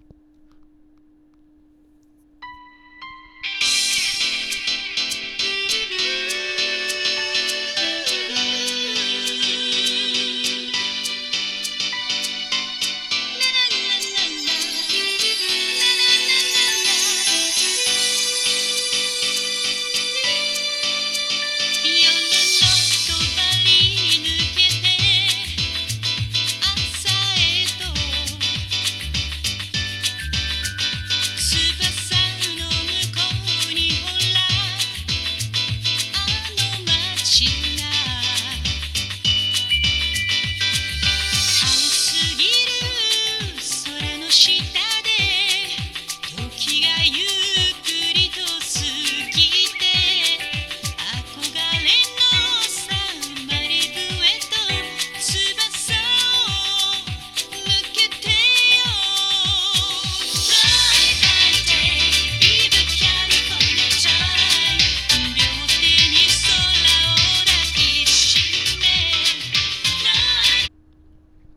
・オリンパス ステレオICレコーダー LS-20M：リニアPCM 44.1kHz/16bit 無圧縮.WAV